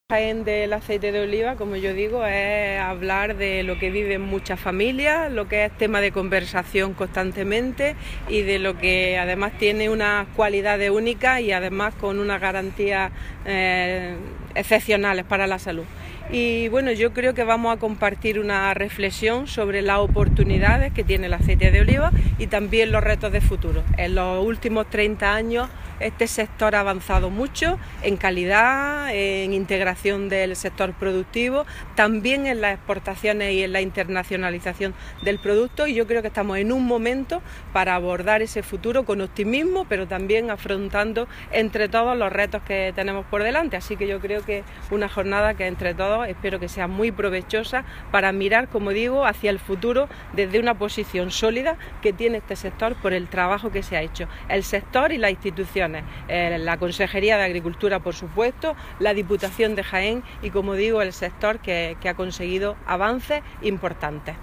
La consejera Carmen Ortiz, en su intervención en el foro de Diario Jaén.
Declaraciones consejera foro